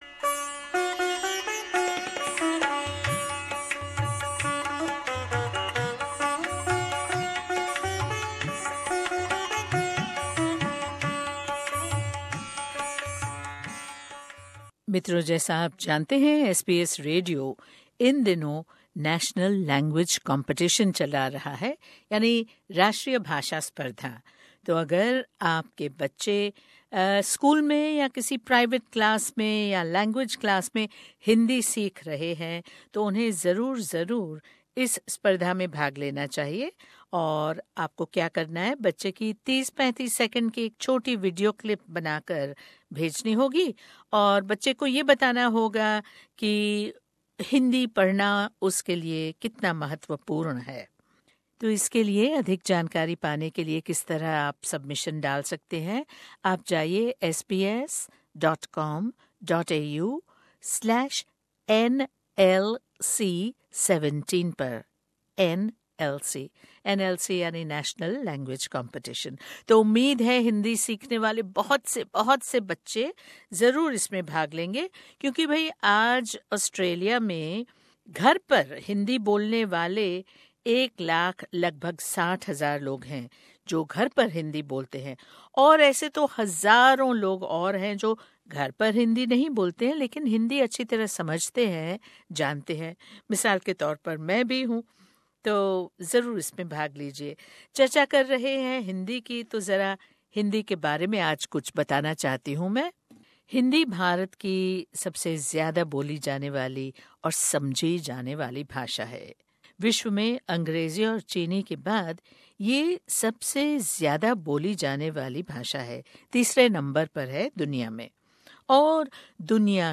We opened the phone lines for our listeners to participate in a talk back telling us about various English words that we unwittingly use in our spoken Hindi......